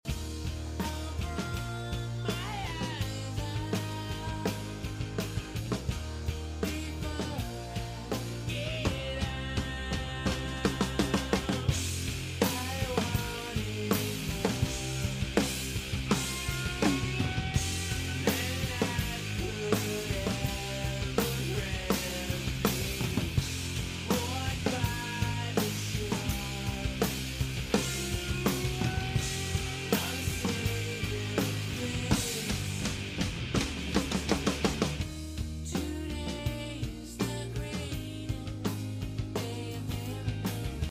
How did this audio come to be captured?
live drum cover